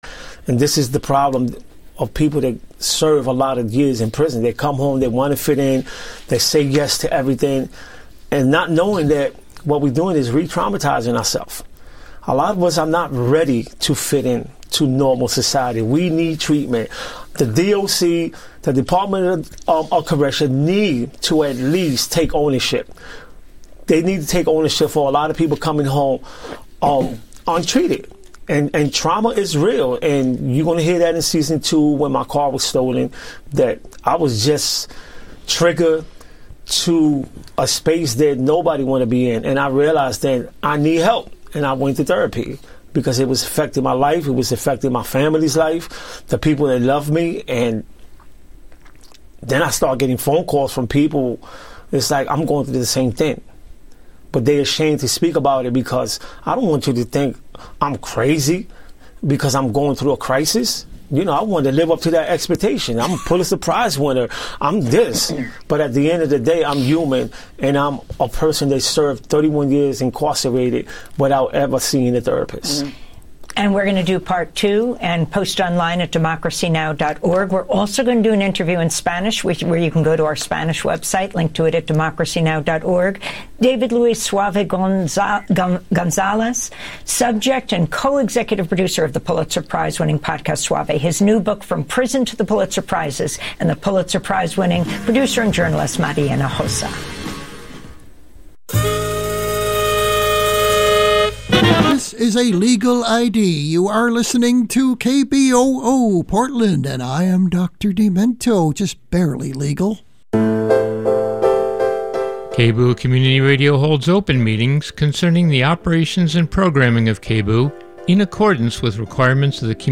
Non-corporate, community-powered, local, national and international news